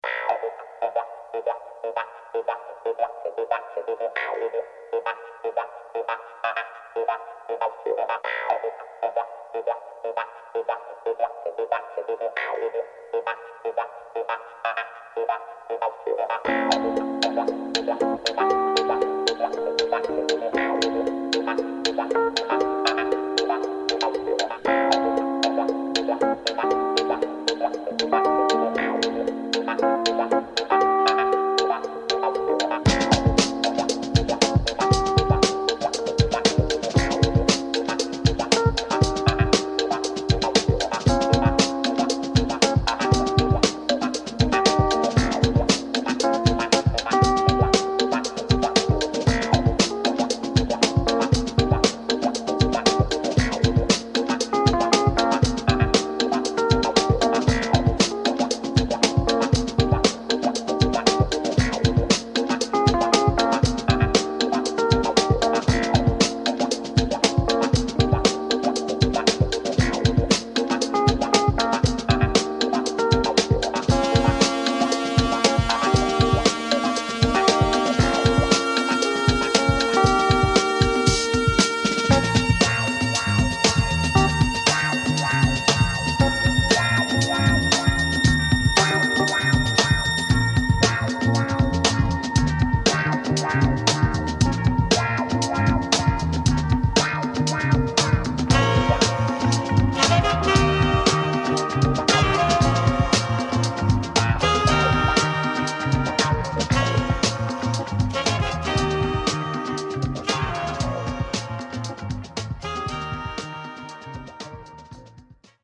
Tags: Funk , Soul , Panama
drums
clavinet
on guitar.
electric bass and keyboards